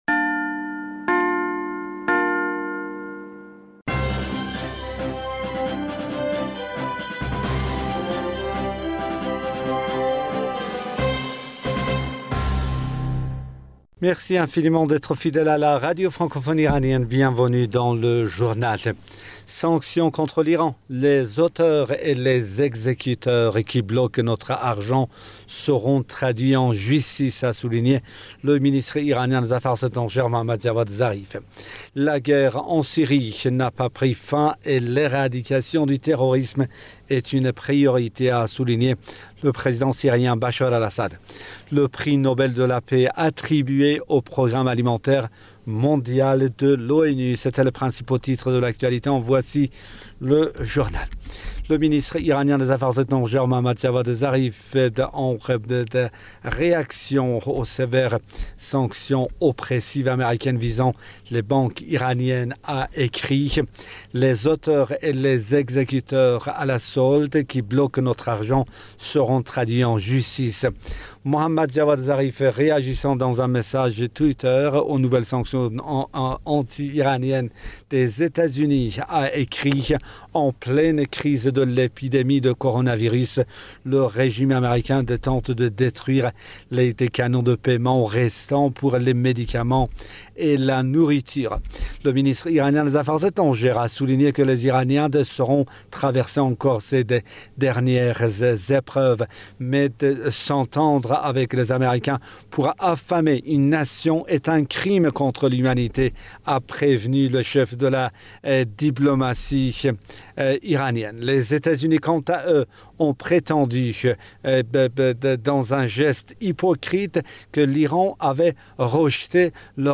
Bulletin d'information du 09 Octobre 2020